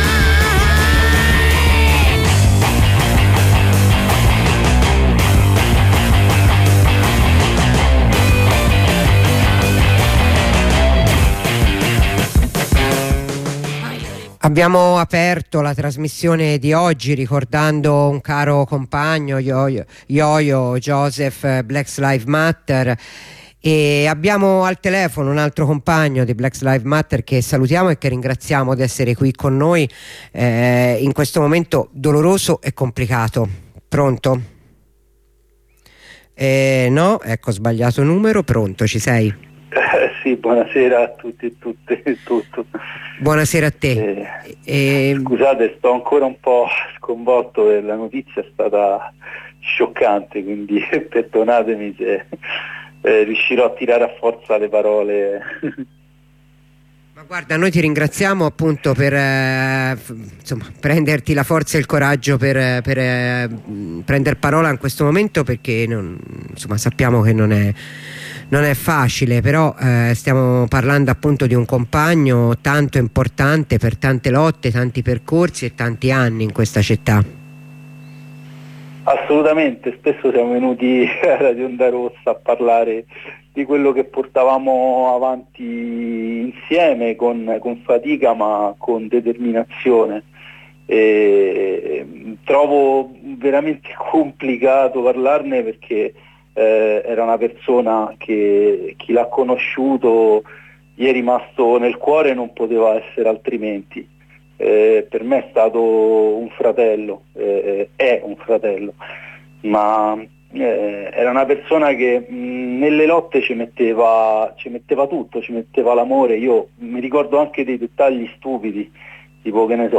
Lo salutiamo con un altro compagno di Black Lives matter Roma.